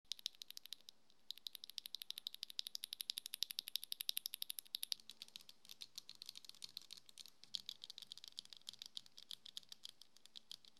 Minimalne tarcie przekłada się zaś na naprawdę cichszą pracę w porównaniu do wielu innych przełączników liniowych.
Sam dźwięk wydawany przez przełączniki można opisać mianem: kremowego i delikatnego.
Nimbus-V3-sound.mp3